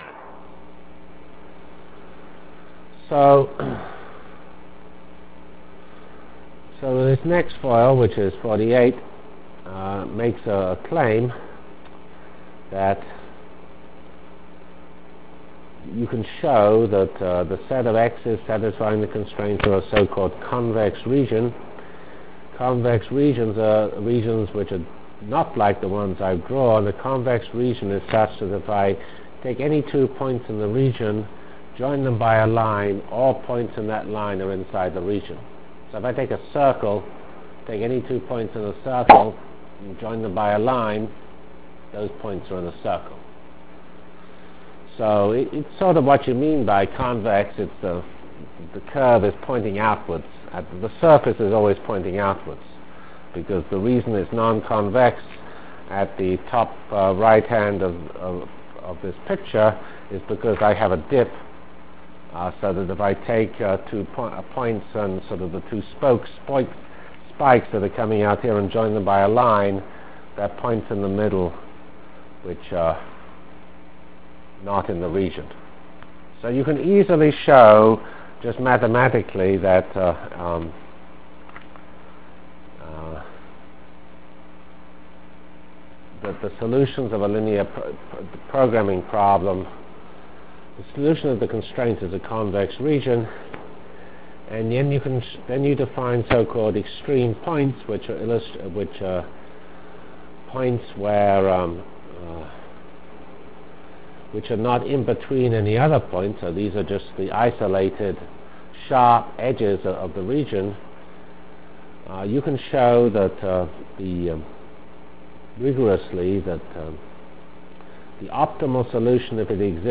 From CPS615-Linear Programming and Whirlwind Full Matrix Discussion Delivered Lectures of CPS615 Basic Simulation Track for Computational Science -- 5 Decemr 96. *